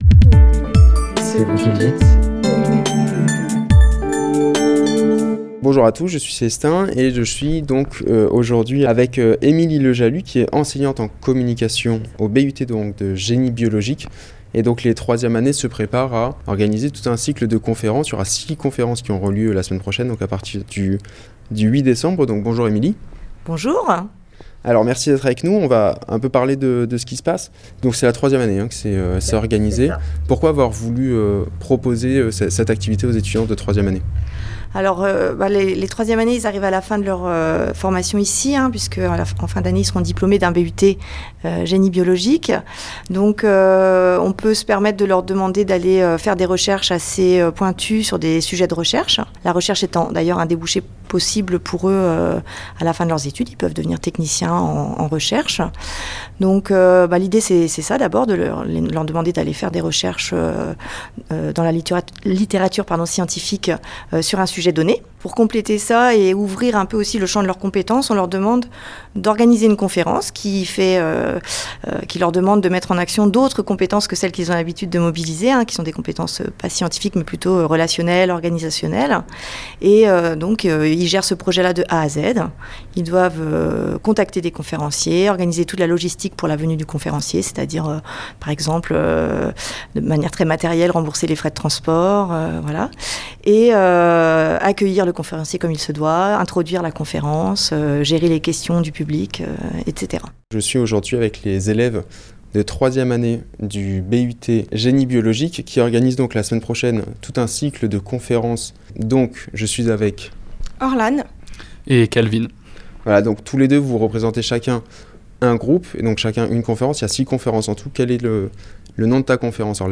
nous détaille le programme et deux élèves nous racontent les coulisses de la préparation.